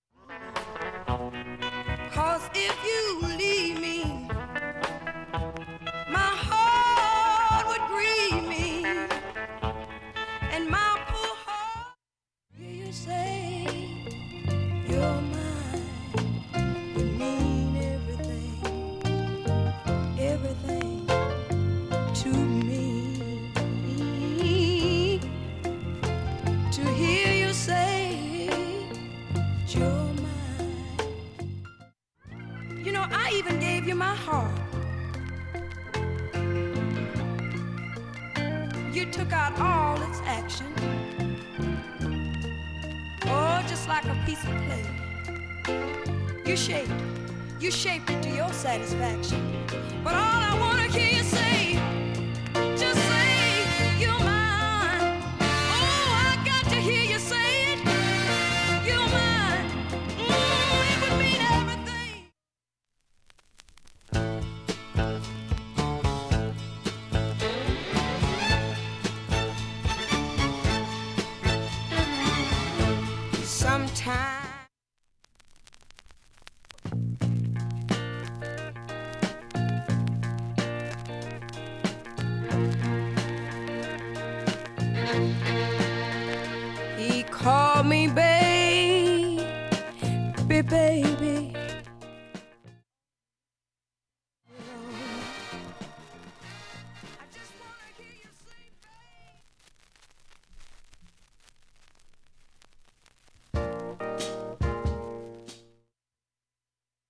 曲間チリ出ますがごく少です
音質良好全曲試聴済み。
彼女の素晴らしい歌唱も見事だが、